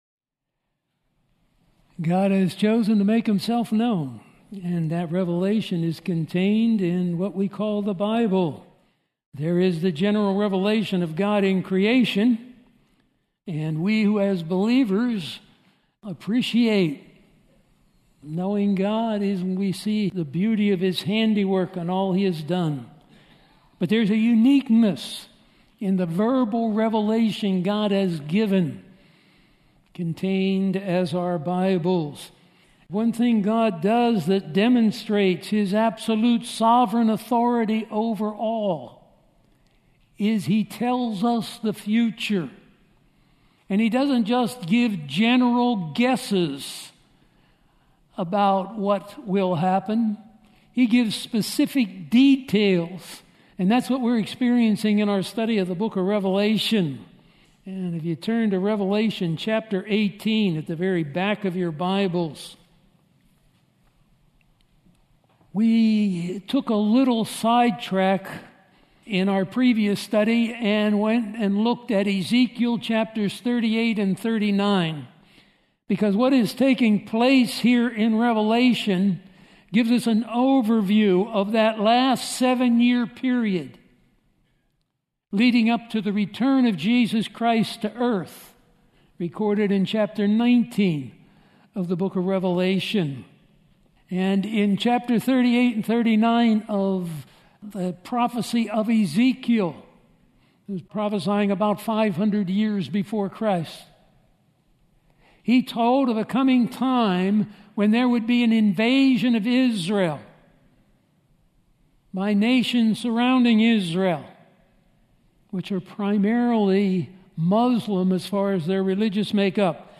Sermons Who and What is Babylon? 7/8/2018 GR 2047 Revelation 18:1-8